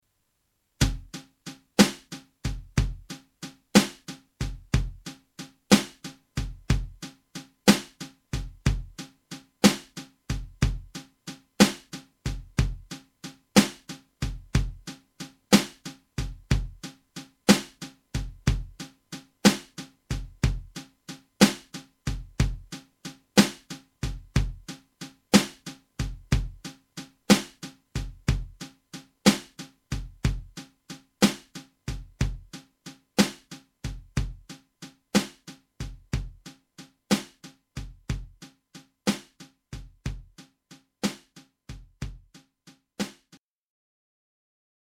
Il blues presenta quale suddivisione ritmica tipica quella che si chiama 12/8 (si legge dodici ottavi), vale a dire che ogni volta che batto il piede per terra per segnare il tempo eseguo tre note (cioè una terzina).
Terzinato 12/8 lento (traccia di batteria)